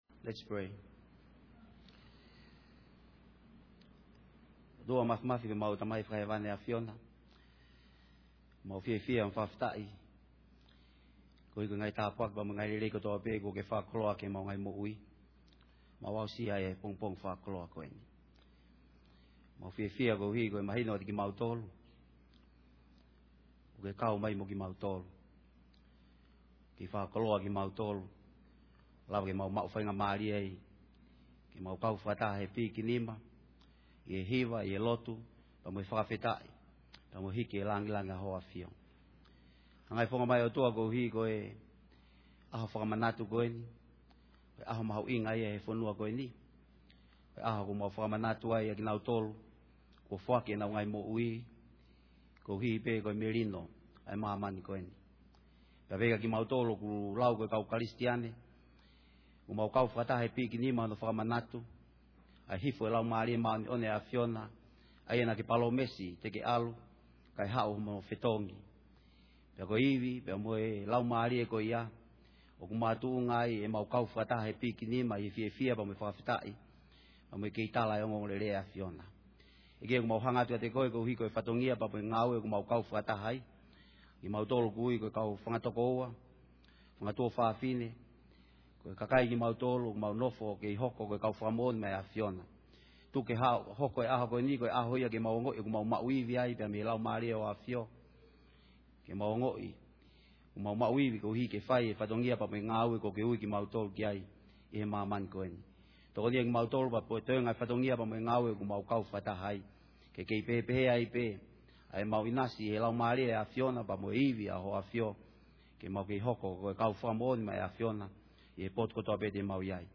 Pentecost Sunday Worship Service
Pentecost Prayer